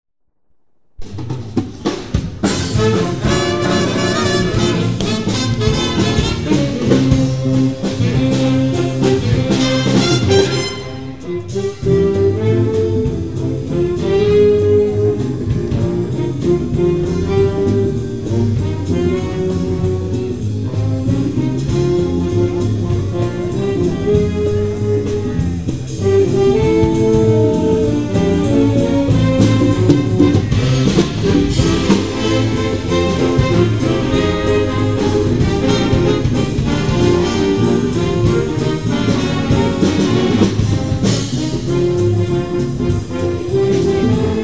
Download an MP3 of the Band in action